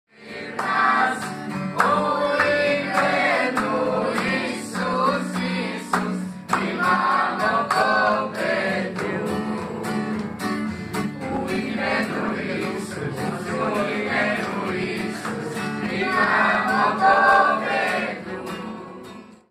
Een korte impressie van een zangdienst in één van de gemeenten die we bezoeken: